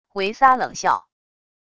维撒冷笑wav音频